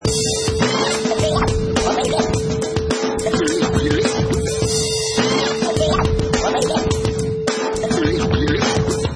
Wav: Scratch 100BT 3
Professional killer vinyl scratch on top of hip hop beat, perfect for sampling, mixing, music production, timed to 100 beats per minute
Product Info: 48k 24bit Stereo
Category: Musical Instruments / Turntables
Try preview above (pink tone added for copyright).